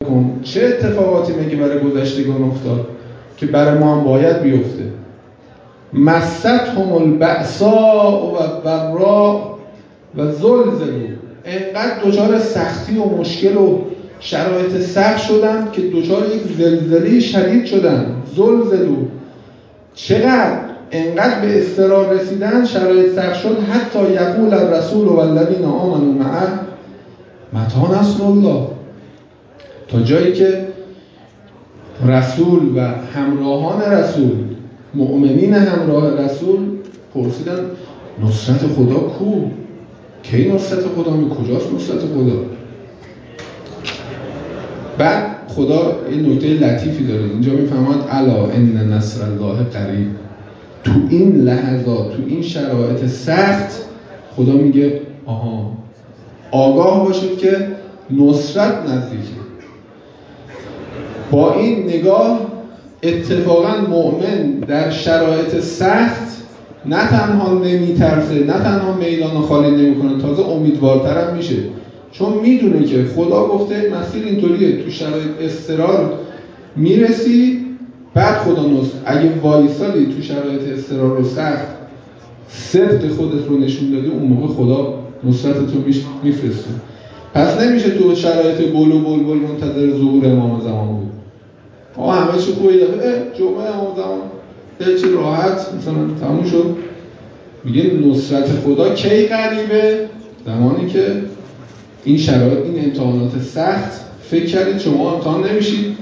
محفل تلاوت و تدبر در قرآن کریم روز گذشته از سوی نهاد نمایندگی مقام معظم رهبری در دانشگاه صنعتی امیرکبیر برگزار شد.